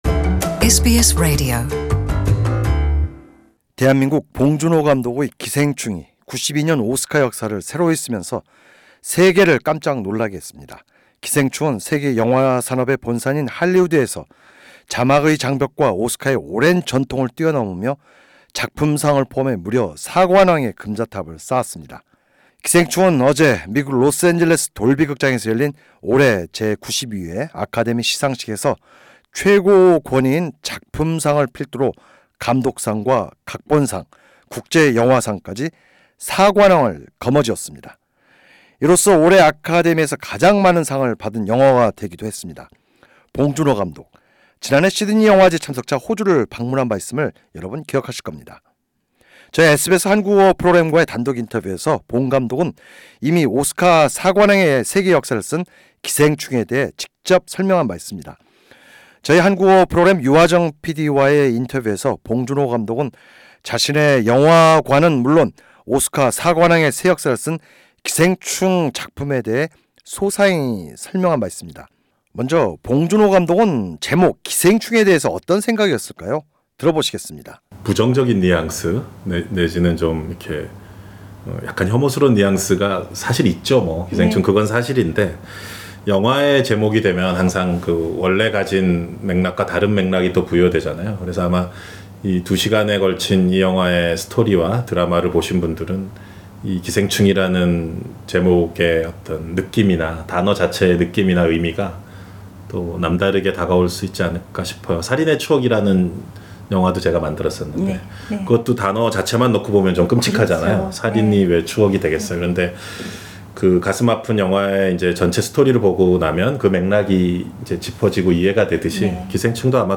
세계 영화 역사를 새로이 쓴 한국의 봉준호 감독은 지난해 시드니 필름 페스티발 참석차 호주를 방문해 SBS 한국어 프로그램과 단독 인터뷰를 갖고 '기생충'에 대해 자세히 설명한 바 있다.